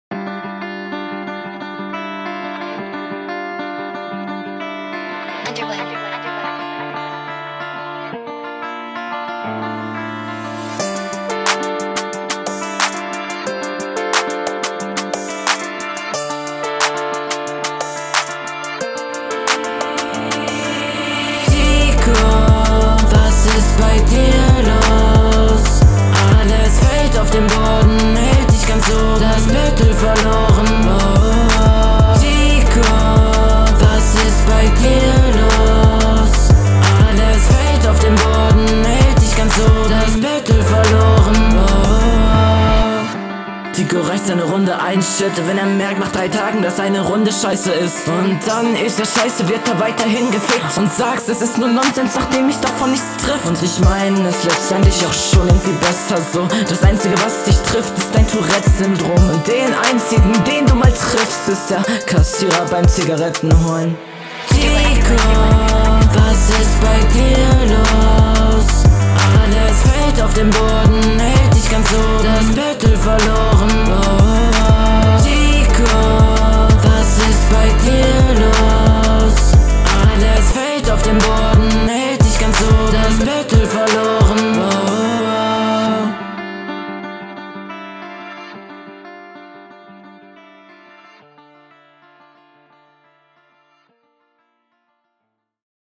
Bevor ich wieder objektiv vote: Die Hook... ist so schön.
Flow: Sehr schön gemacht, Stimmeinsatz find ich hier schon besser!